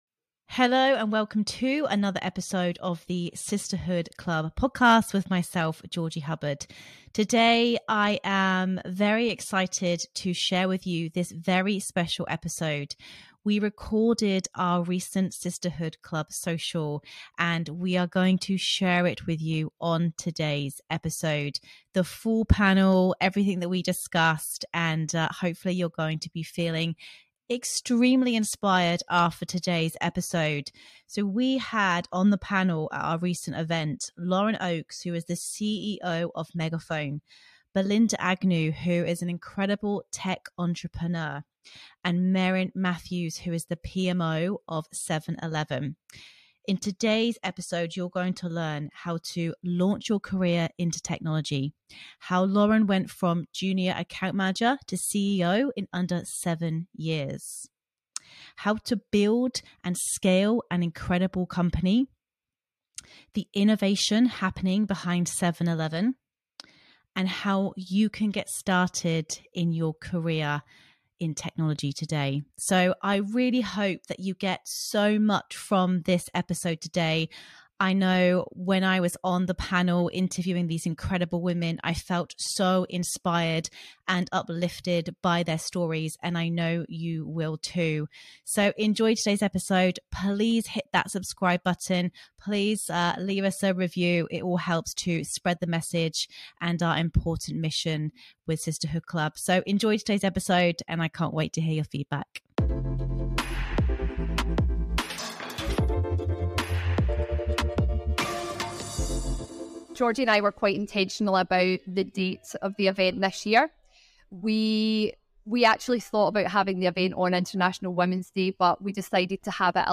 Utilising AI for Business and Career Growth - Full Panel Discussion
Today's episode is straight from our recent Sisterhood Social Event in Melbourne.